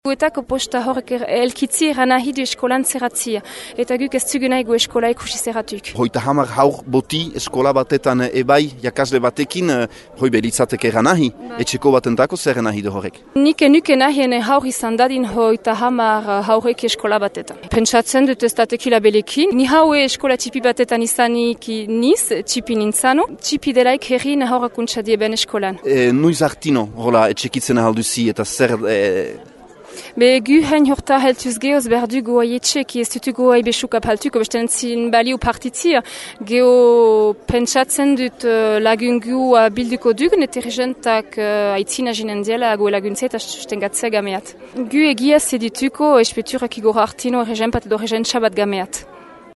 Gamere-Zihigako ama bat :